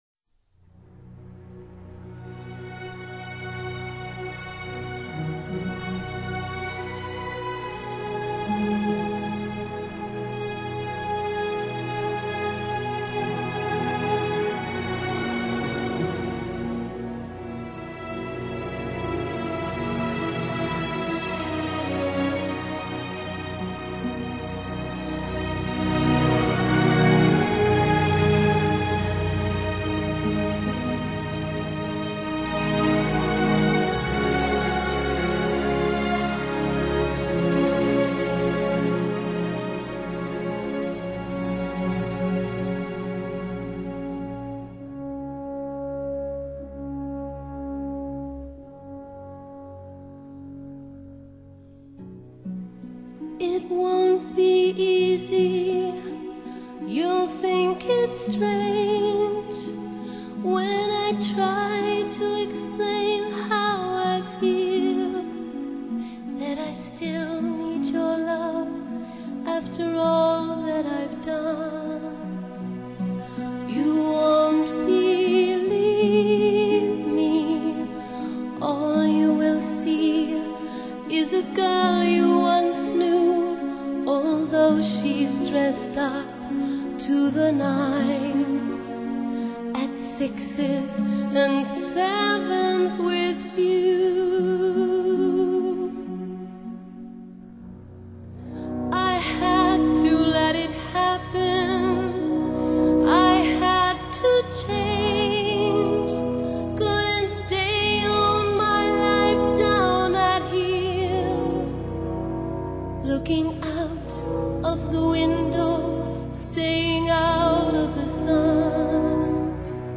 Genre: Pop/Rock, Soundtrack